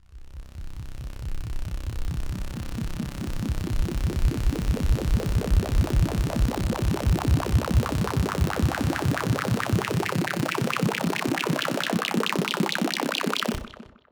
K-5 Psycho Psweep.wav